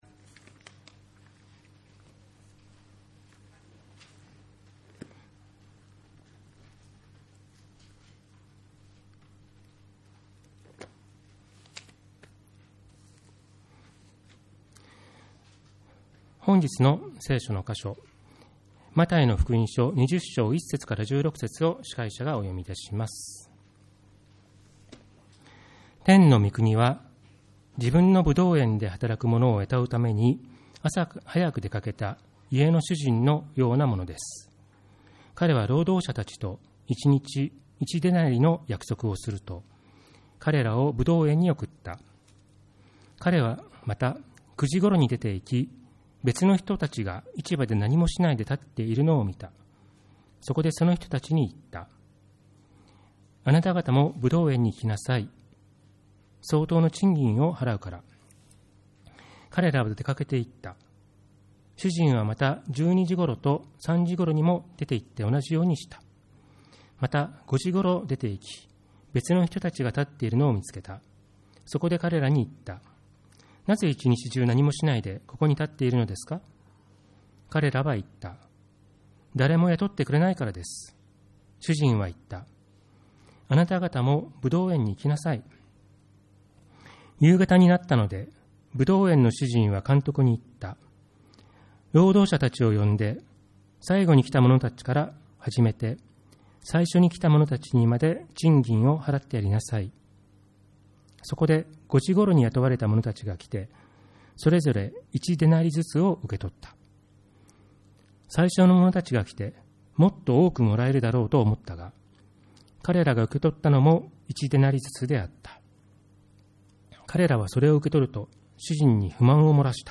2024.2.4 主日礼拝
礼拝メッセージ